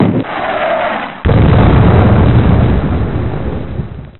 Explosion+2
Category: Sound FX   Right: Personal